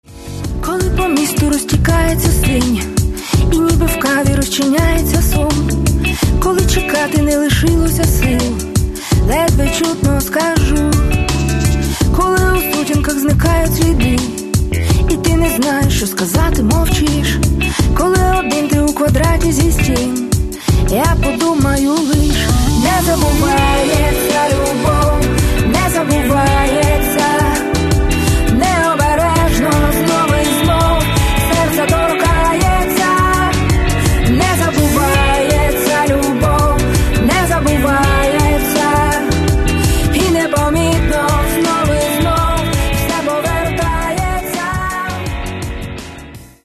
Каталог -> Поп (Легкая) -> Сборники